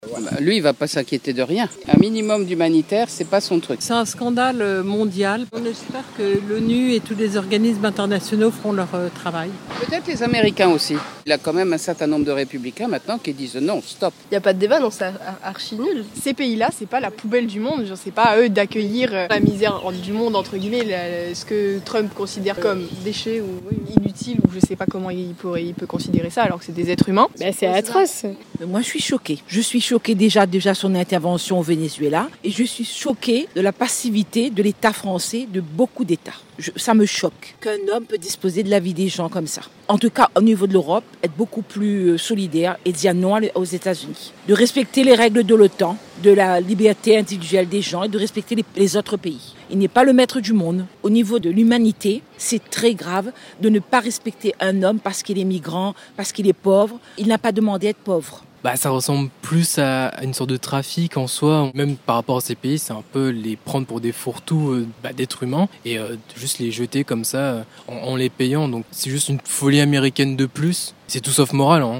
Parmi elles, cette auditrice ne mâche pas ses mots.